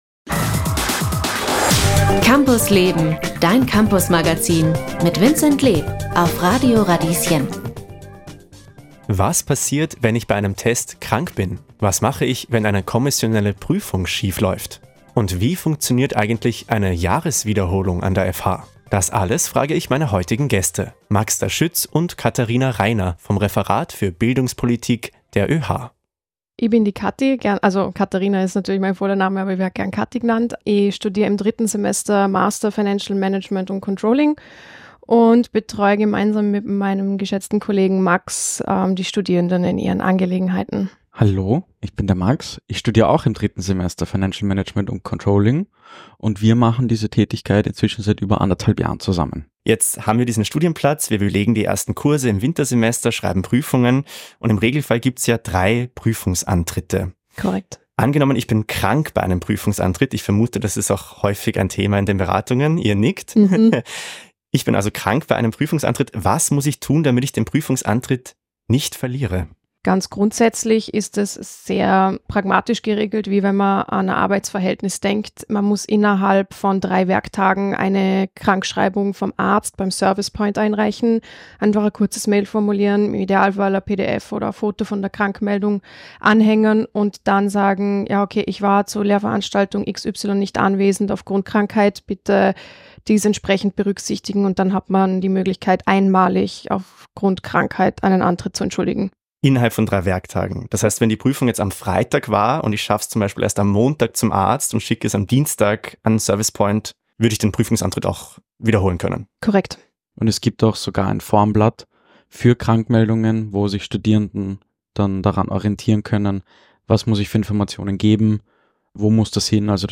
Diese Podcast-Folge ist ein Ausschnitt aus der Campus Leben-Radiosendung vom 23. Oktober 2024.